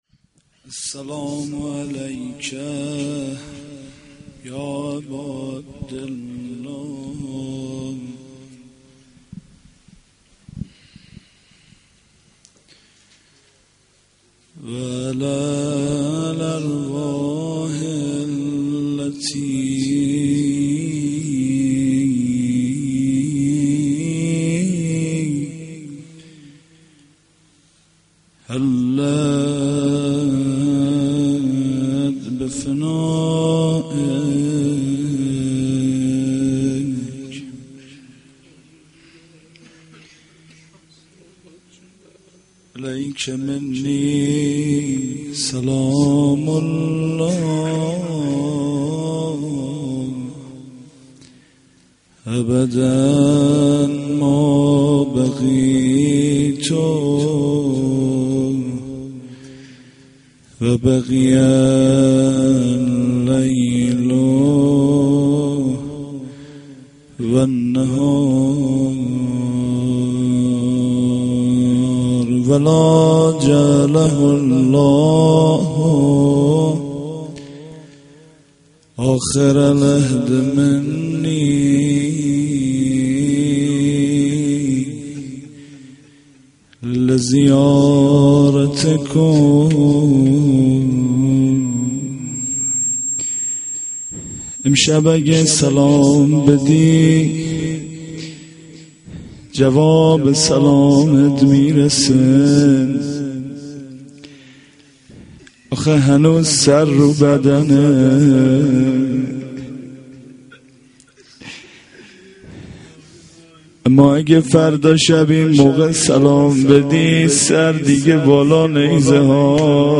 sh-10-moharram-92-roze.mp3